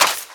High Quality Footsteps
STEPS Sand, Run 13.wav